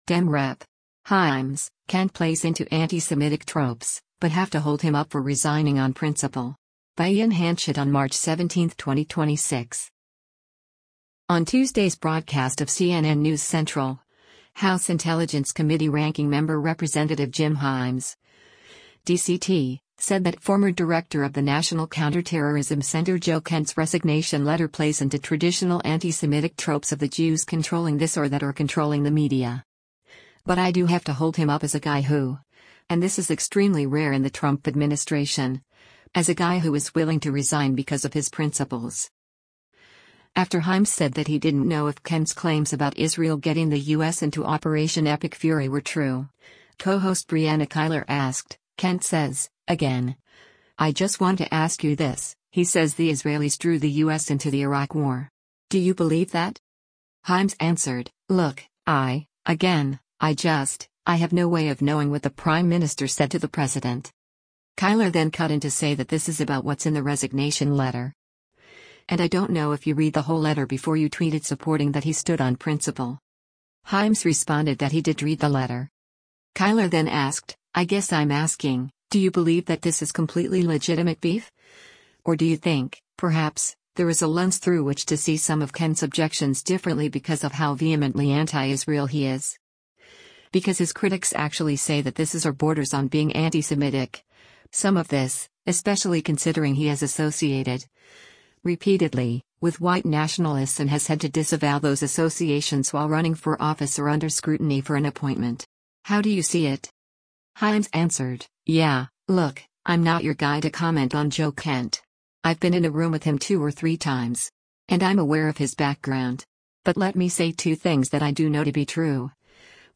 On Tuesday’s broadcast of “CNN News Central,” House Intelligence Committee Ranking Member Rep. Jim Himes (D-CT) said that former Director of the National Counterterrorism Center Joe Kent’s resignation letter “plays into traditional antisemitic tropes of the Jews controlling this or that or controlling the media.”